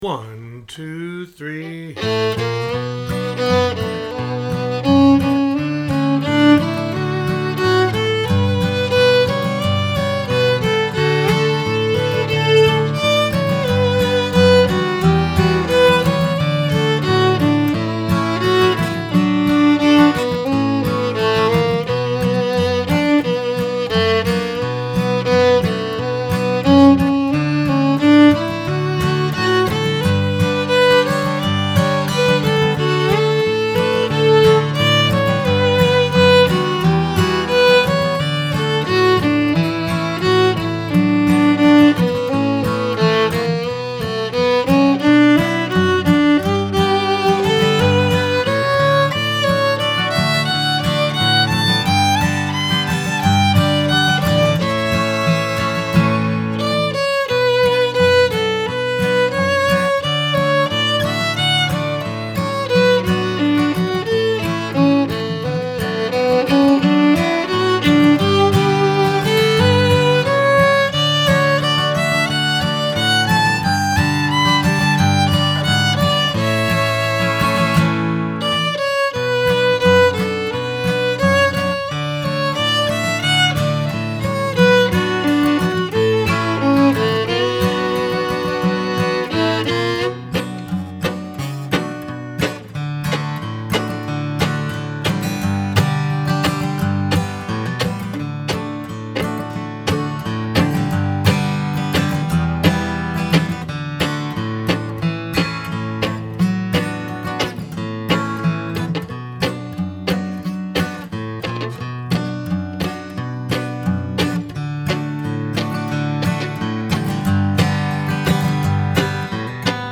video lesson
fiddle